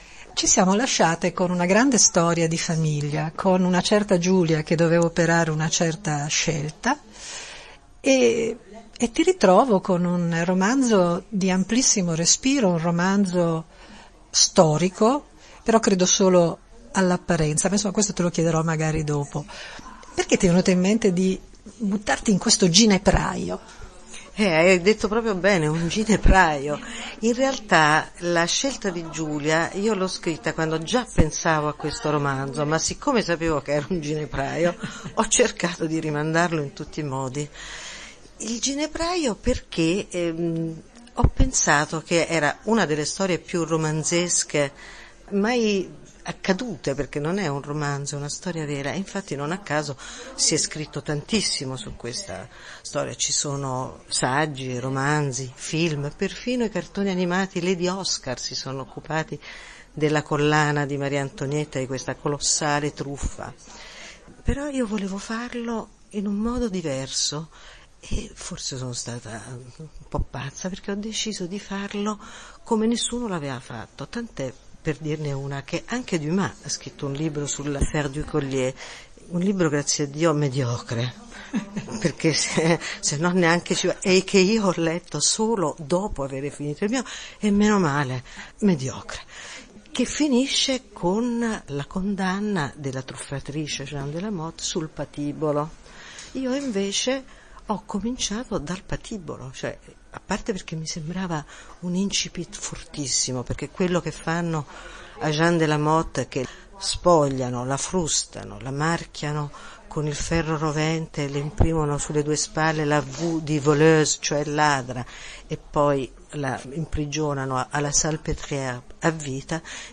Ecco l’intervista